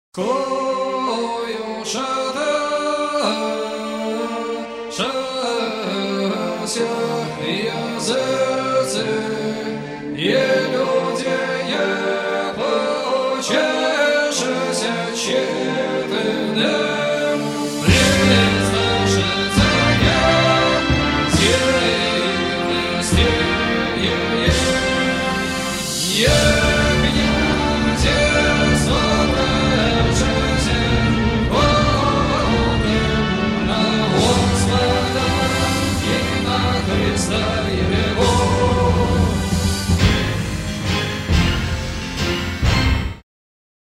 фрагмент выступления ( 45 сек.)
AUDIO, stereo